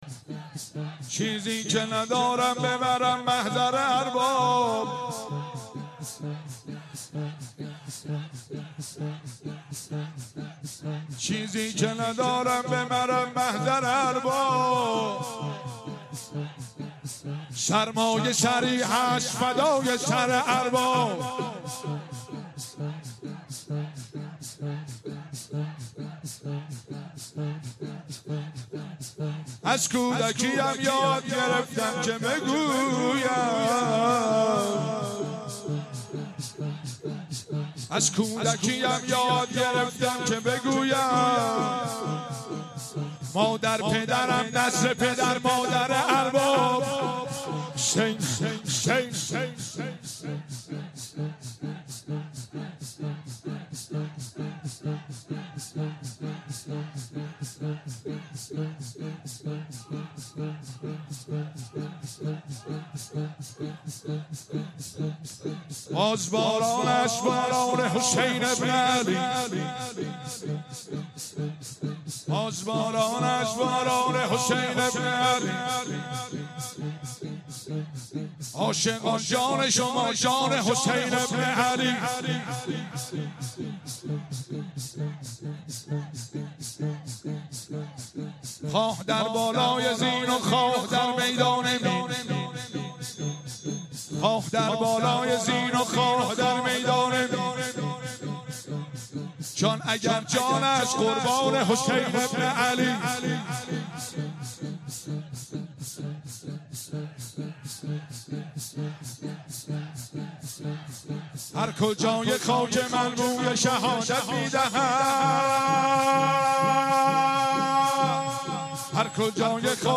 مراسم شب اول فاطمیه ۱۳۹۶
فاطمیه ۱۳۹۶